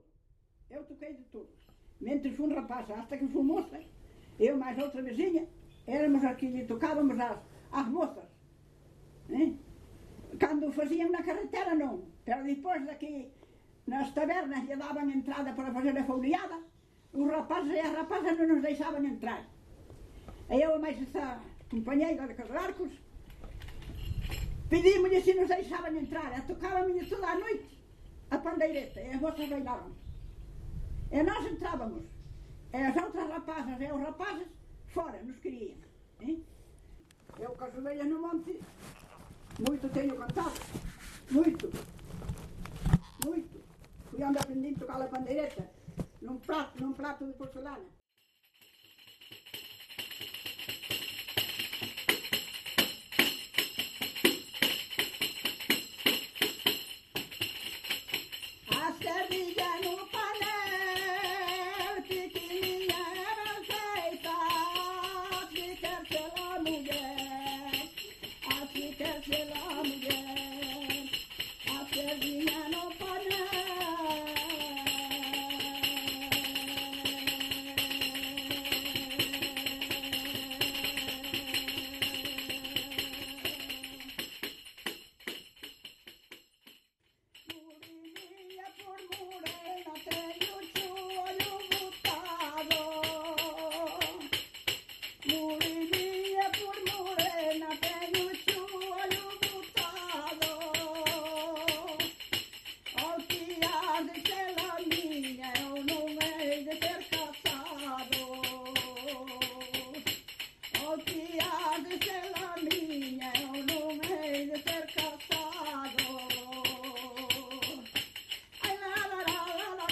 Palabras chave: coplas
Tipo de rexistro: Musical
Xénero: Muiñeira
Instrumentación: Percusión, Voz
Instrumentos: Pandeireta, Voz feminina